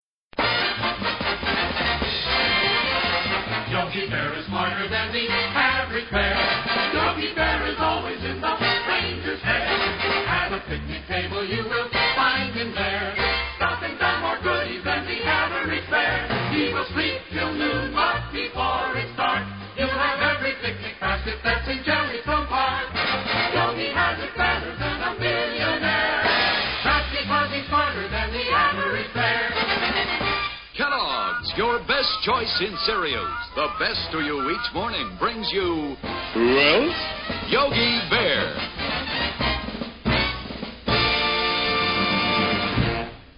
main theme